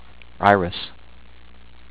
eye-riss